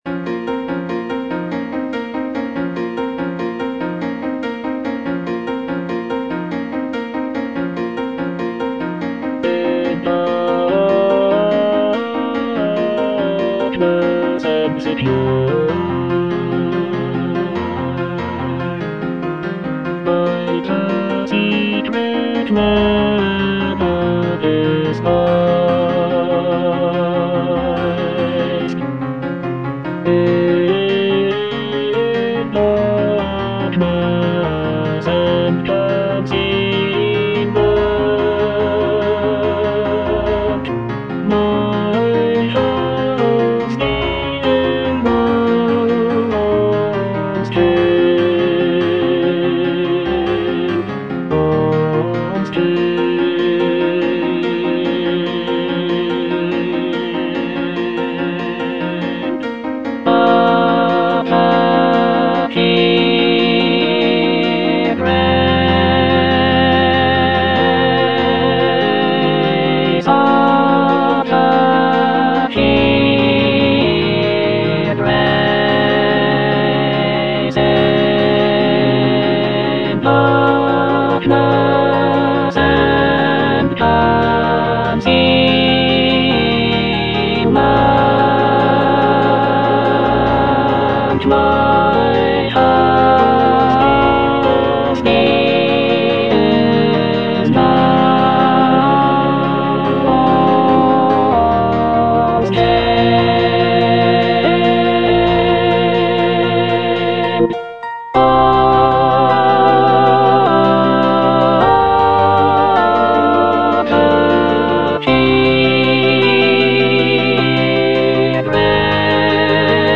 (alto I) (Emphasised voice and other voices) Ads stop
choral work